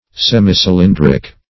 Search Result for " semicylindric" : The Collaborative International Dictionary of English v.0.48: Semicylindric \Sem`i*cy*lin"dric\, Semicylyndrical \Sem`i*cy*lyn"dric*al\a. Half cylindrical.